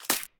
Sfx_creature_babypenguin_hop_04.ogg